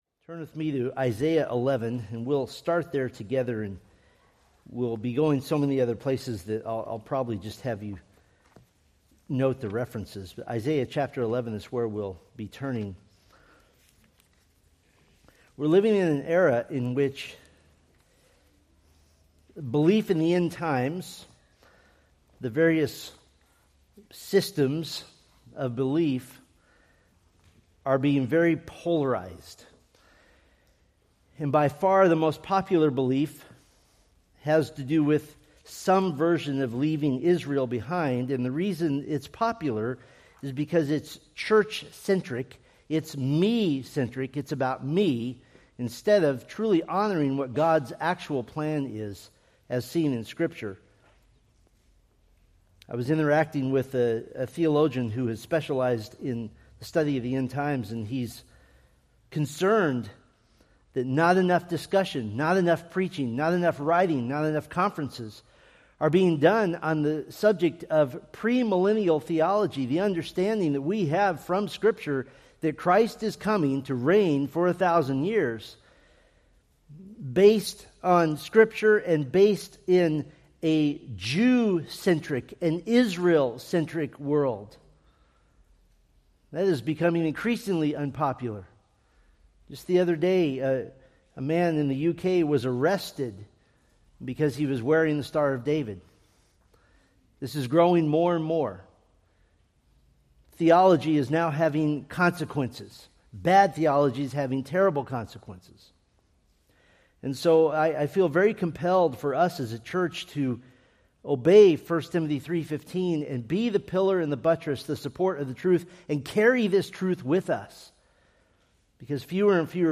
Preached October 19, 2025 from Selected Scriptures